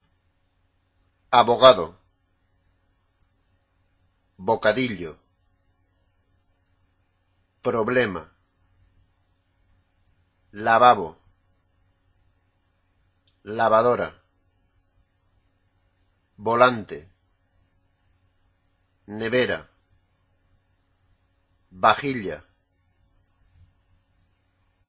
Escucha las siguientes palabras y fíjate en su pronunciación.
En español, la "b" y la "v" se pronuncian igual.